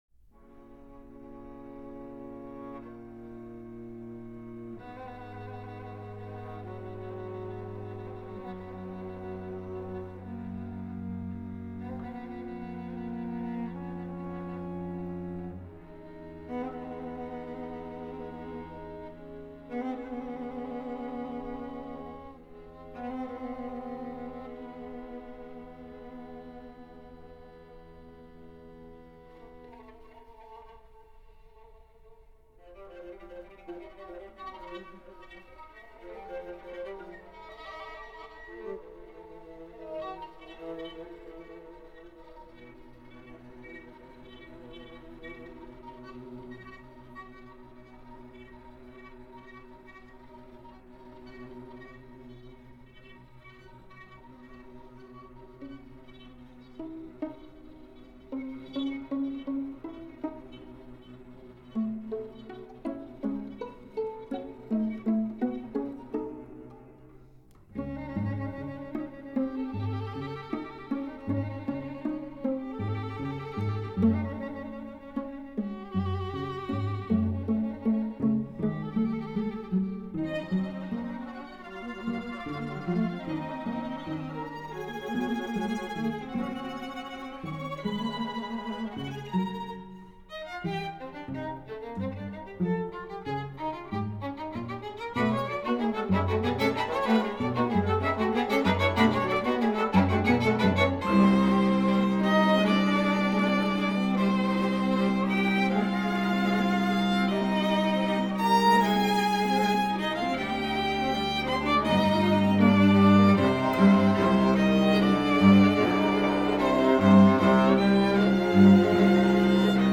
Musique Contemporaine